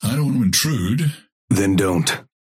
Dynamo and Grey Talon conversation 1